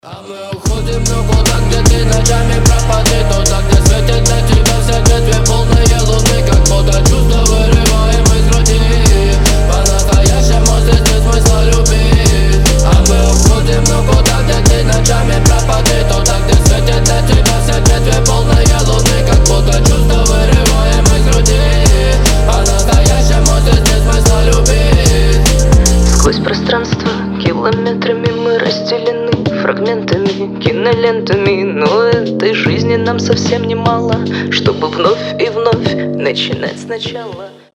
• Качество: 320, Stereo
русский рэп
дуэт